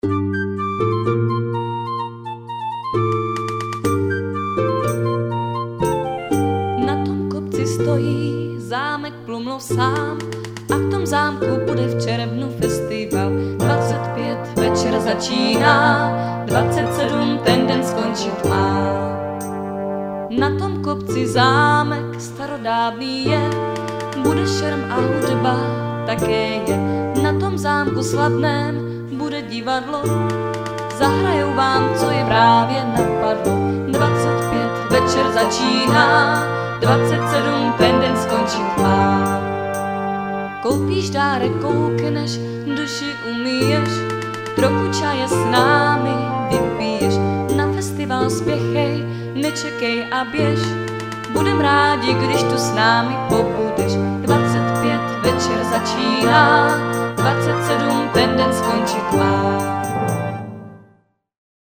Znělka festivalu (1.48 MB, formát mp3)